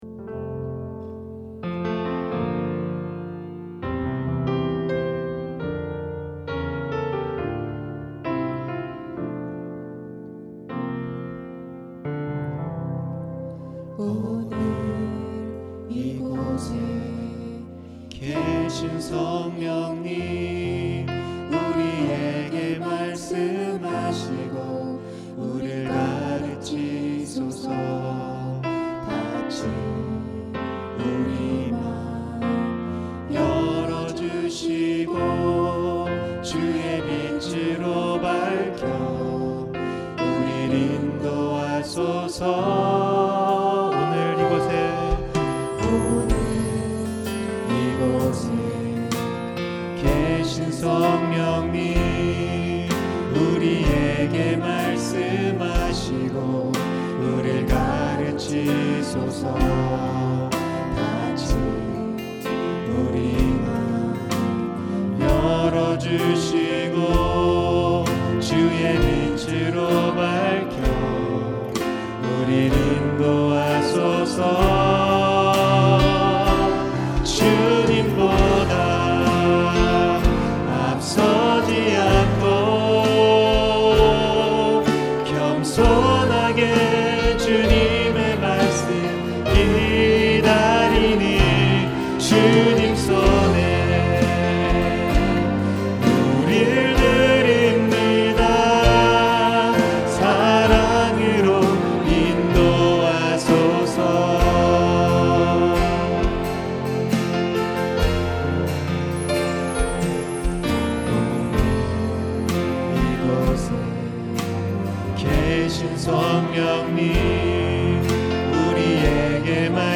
찬양 음악